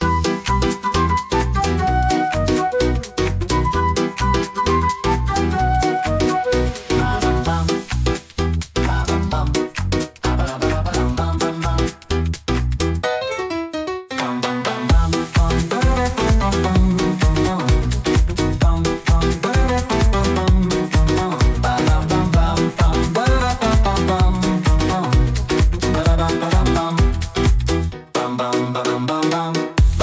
a square dance Patter